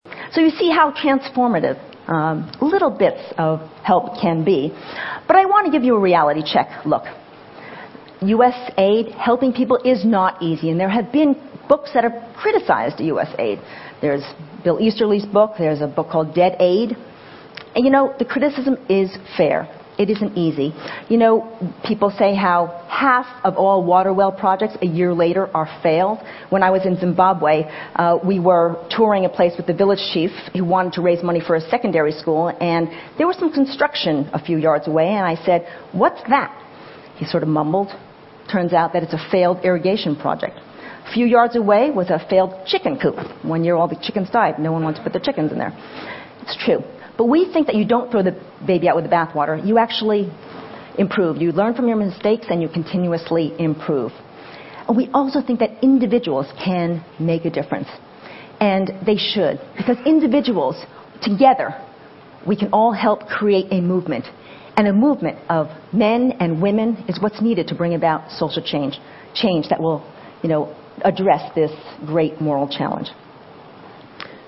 TED演讲:本世纪最大的不公(7) 听力文件下载—在线英语听力室